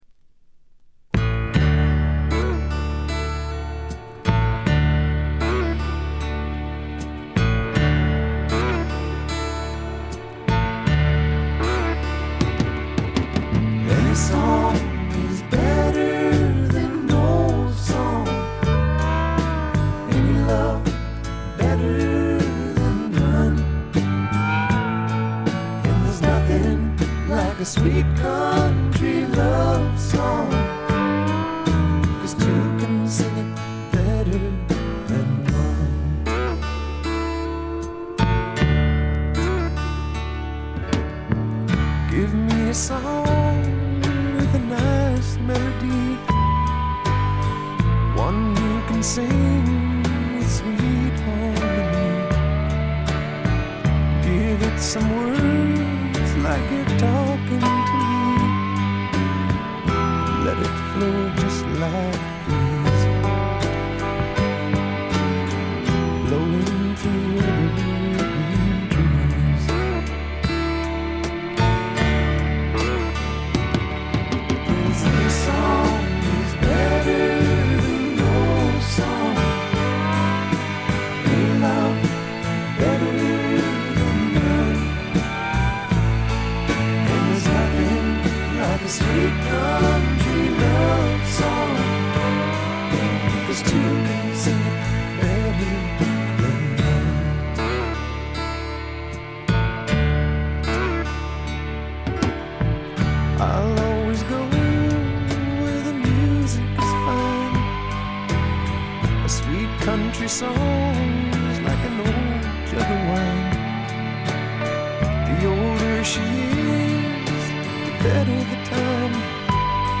部分試聴ですがほとんどノイズ感無し。
試聴曲は現品からの取り込み音源です。
Banjo, Harp
Fiddle
Steel Guitar